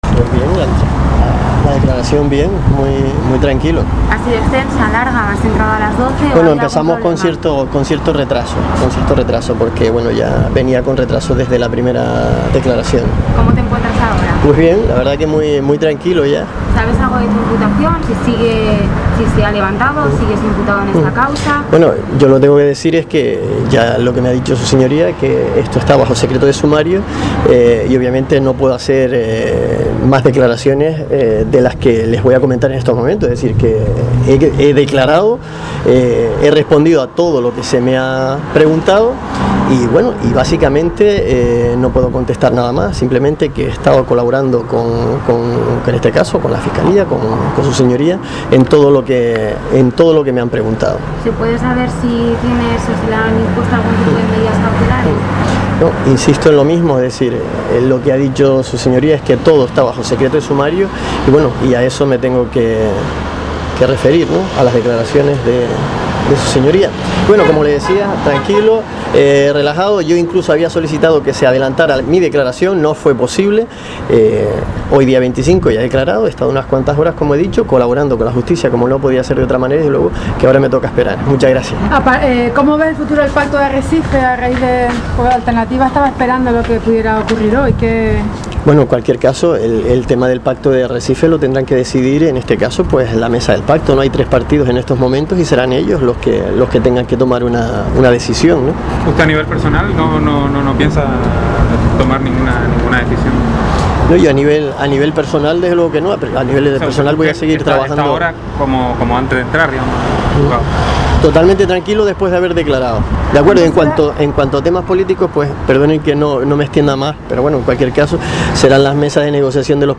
/ Escuche las declaraciones del primer teniente de alcalde al salir de los Juzgados